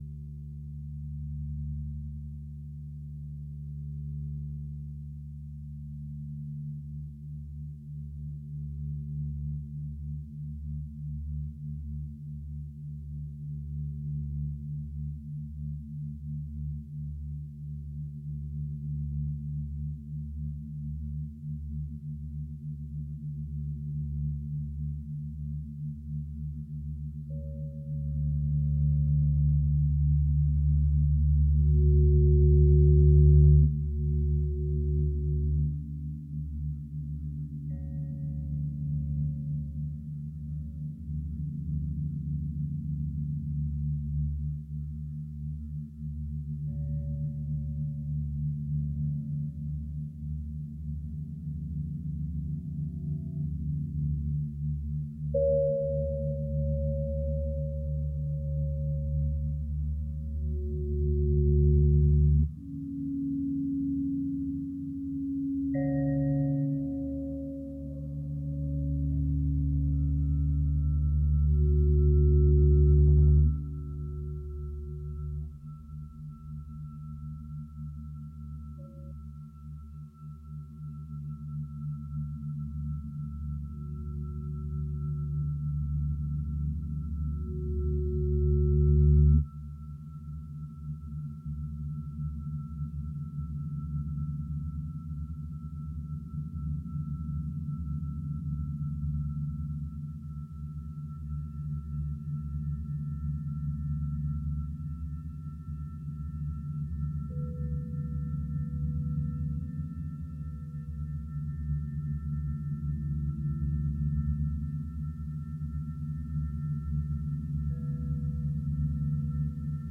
Rimbaud feutré. Rimbaud susurré. Rimbaud suranné. Rimbaud salon de thé ?
les déchirements électroniques entrent en collision avec le saxophone, la voix se fraie un chemin entre la trompette et les envolées de la flûte Shakuhachi
Mix-Rimbaud-est-un-autre-Live-5-Master-MP3.mp3